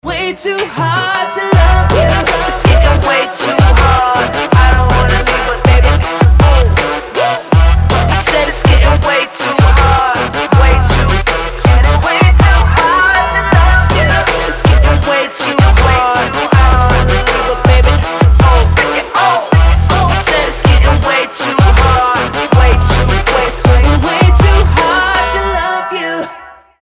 • Качество: 320, Stereo
dance
Electronic
EDM
Bass